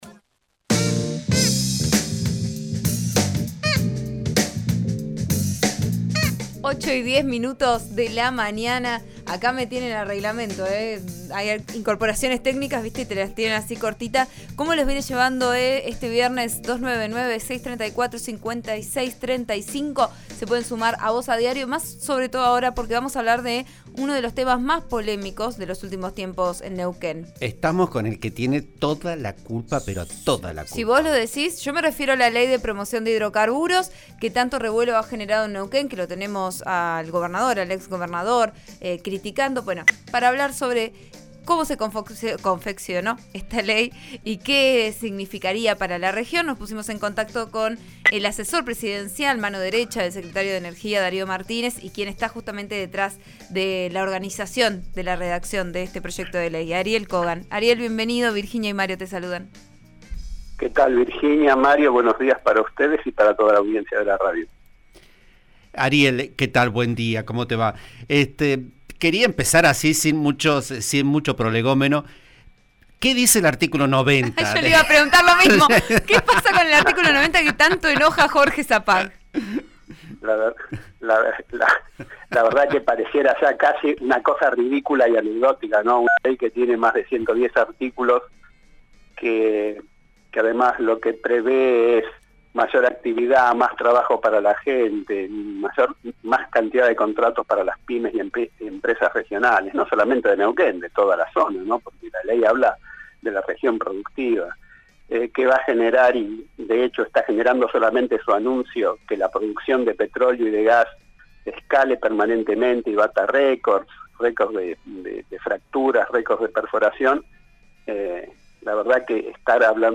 Ariel Kogan, asesor presidencial, en en declaraciones al programa Vos a Diario de RN RADIO (89.3), aseguró que el único que se opone es el gobernador Omar Gutiérrez, acompañado por el exgobernador Jorge Sapag.